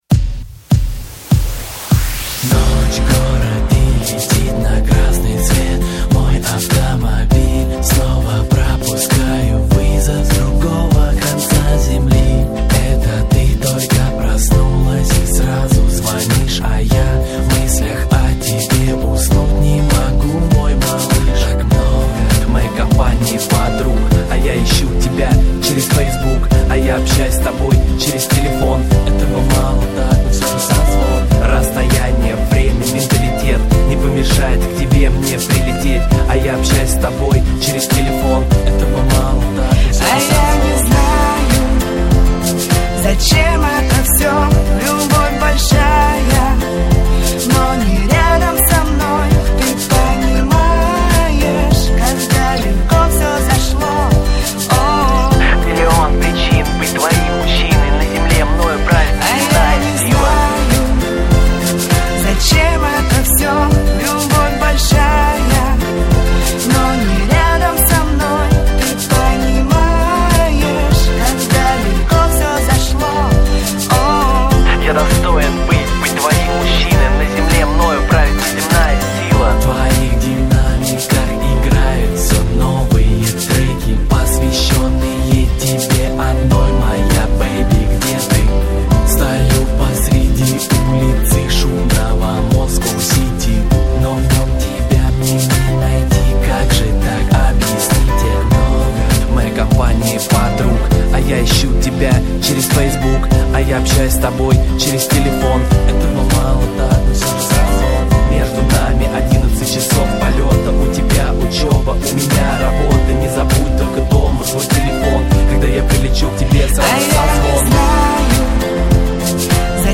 Категория: Попсовые песни
хип-хоп, Дрова, Русский реп